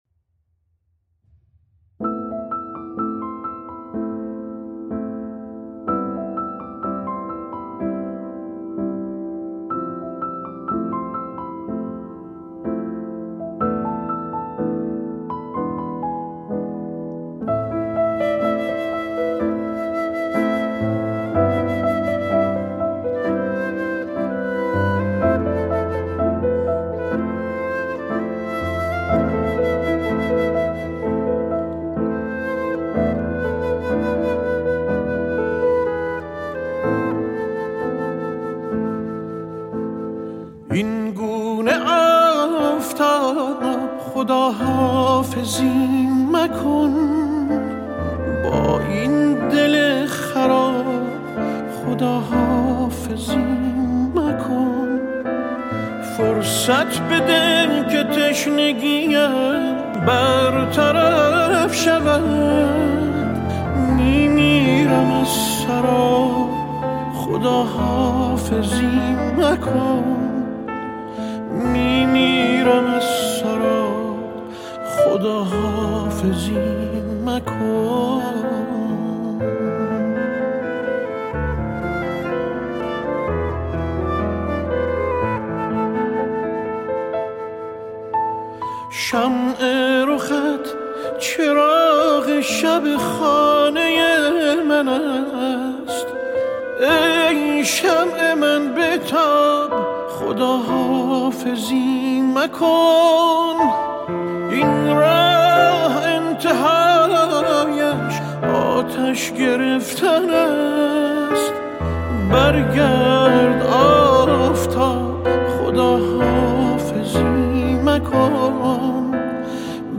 پیانو
فلوت